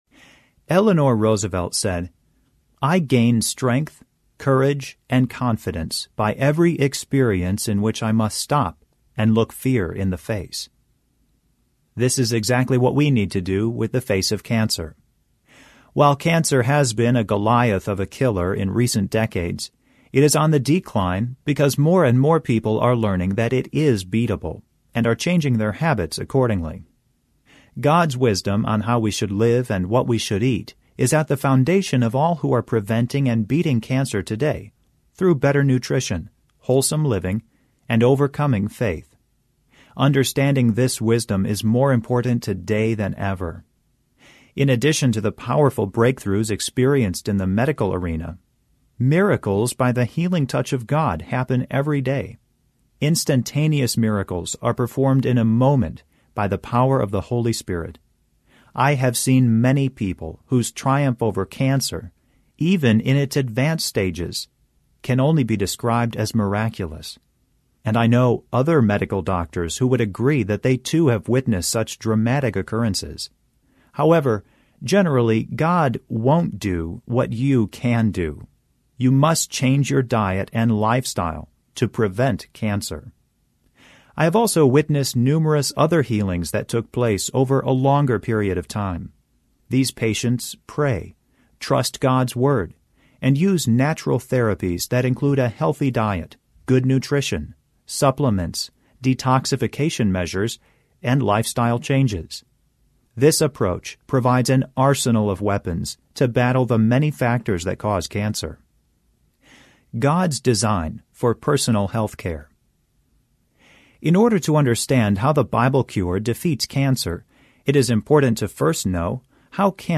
Narrator
2.8 Hrs. – Unabridged